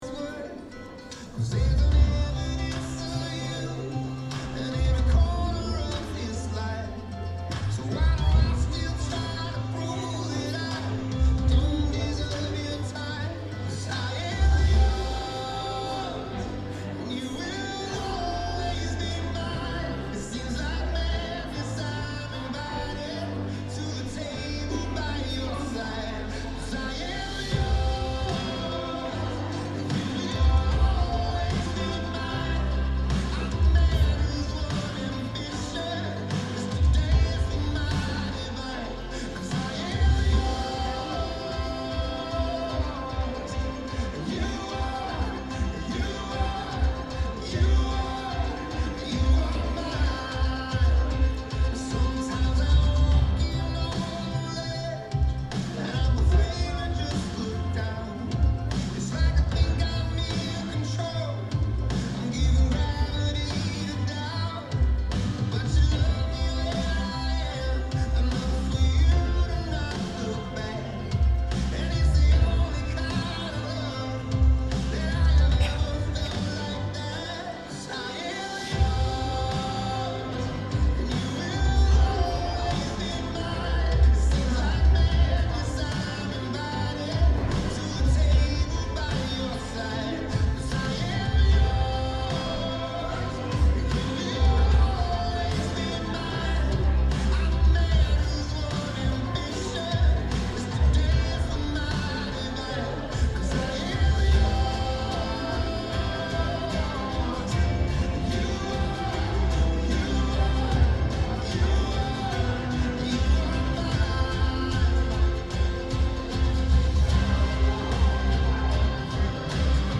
Sermons | Mission 72